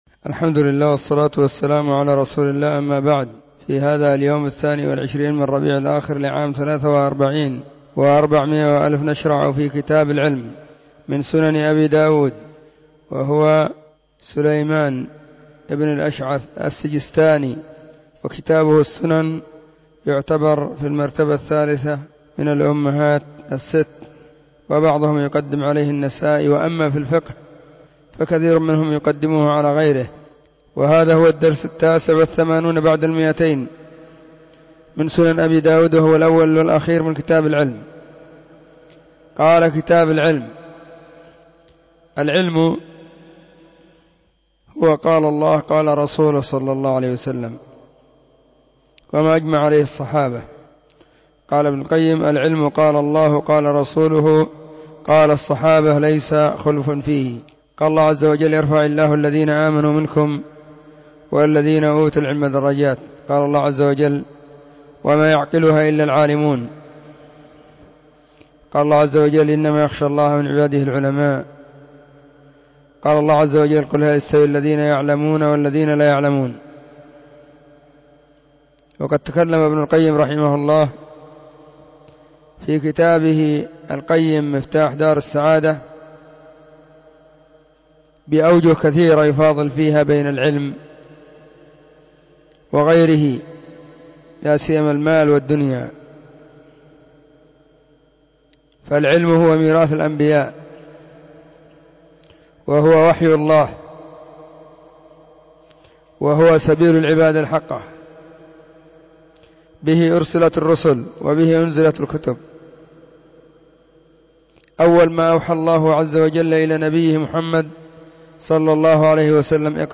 🕐 [بعد صلاة العصر في كل يوم الجمعة والسبت]
📢 مسجد الصحابة بالغيضة, المهرة، اليمن حرسها الله.